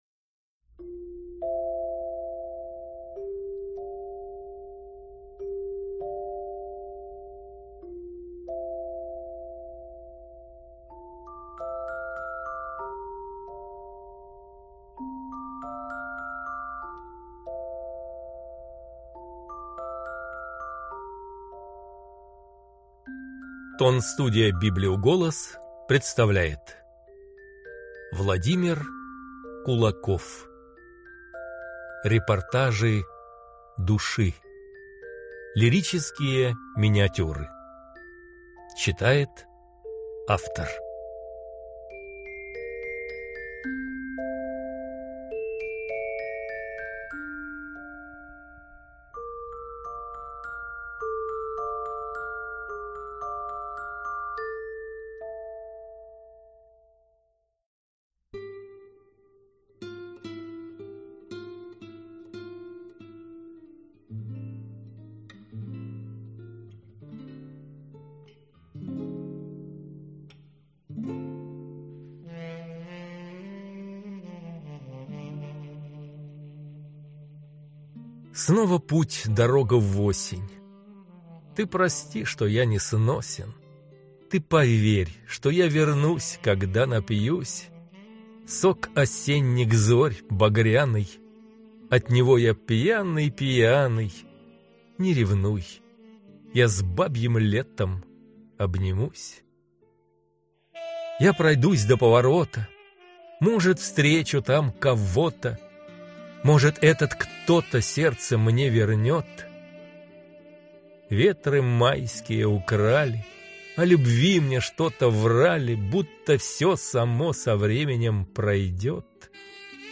Аудиокнига Репортажи Души | Библиотека аудиокниг